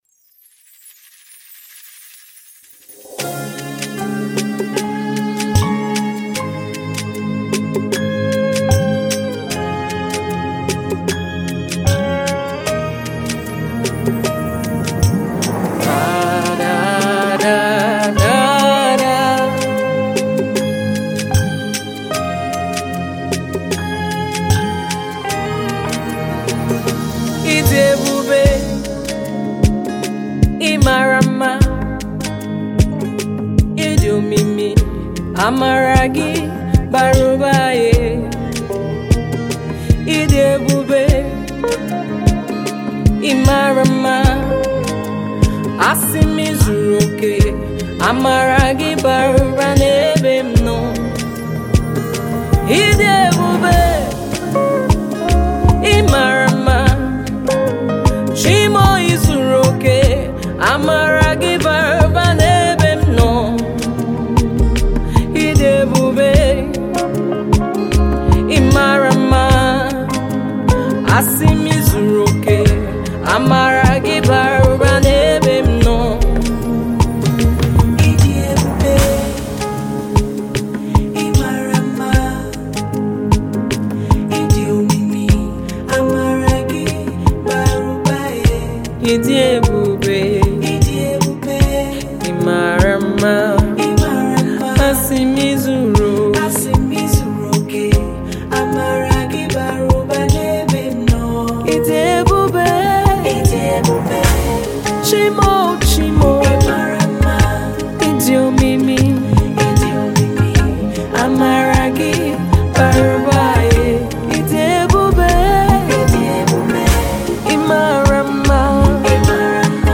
Versatile Gospel music artiste